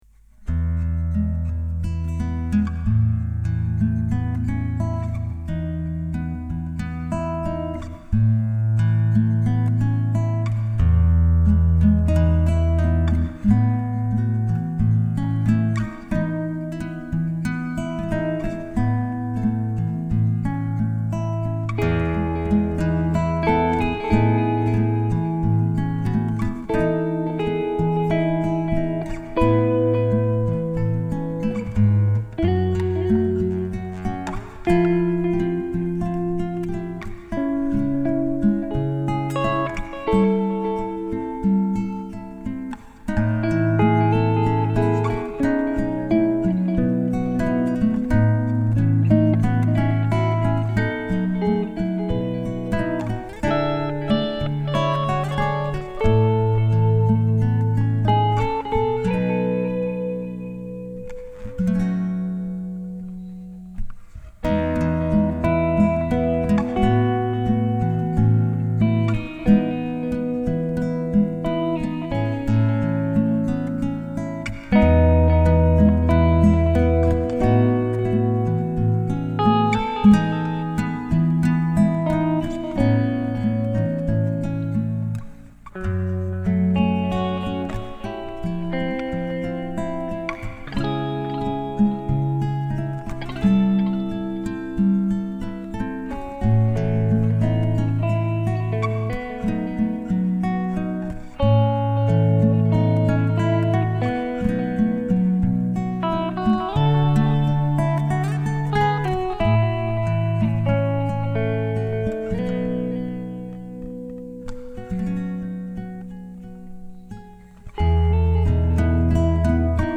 nueva canción en guitarra: aimlessly
Aquí va una melodía desnortada grabada hoy domingo. He utilizado la Ibanez G5ECE-AM, el pedal para acústicas ZOOM A2.1u y la pedalera BOSS ME-70 para la guitarra eléctrica.